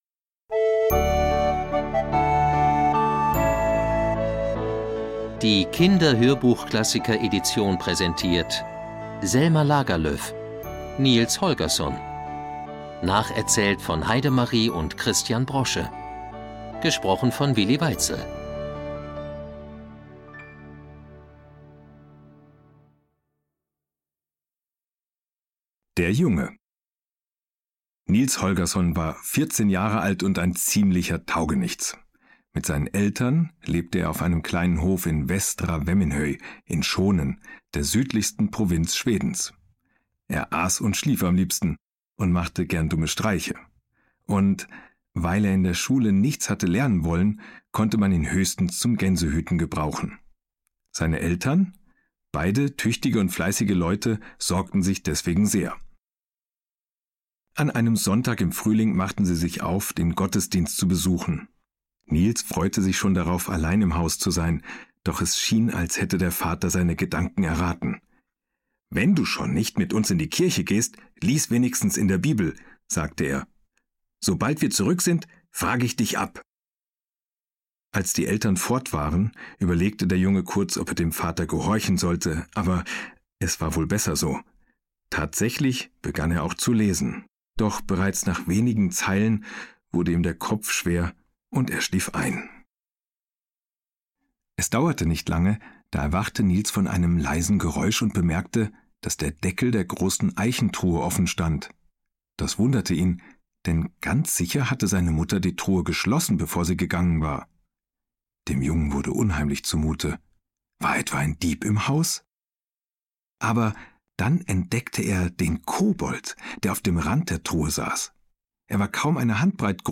Willi Weitzel (Sprecher)
Dieser Hörbuch-Klassiker darf in keinem Kinderzimmer fehlen!
Sprecher: Willi Weitzel ist ein bekannter Reporter und Moderator.